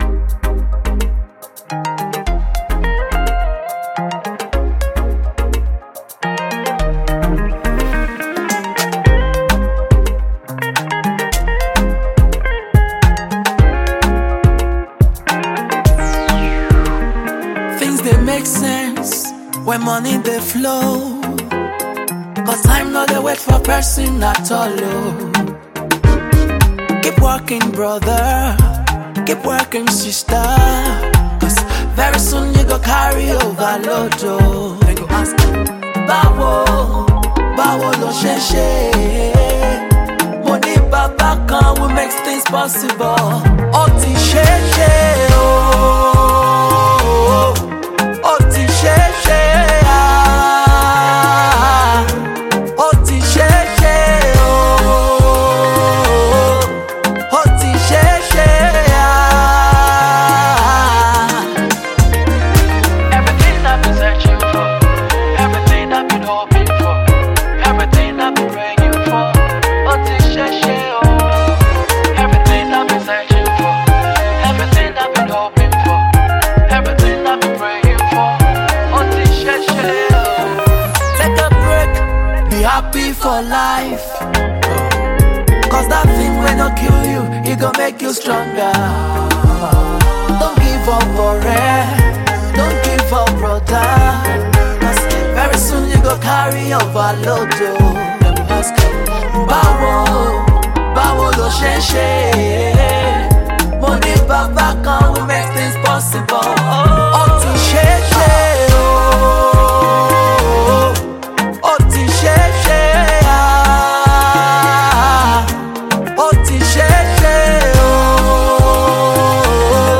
Award winning Gospel Music Minister